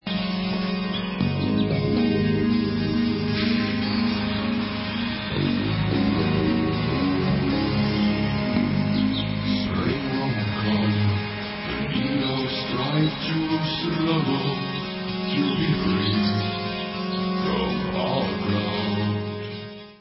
Rock - Speed/Thrash/Death Metal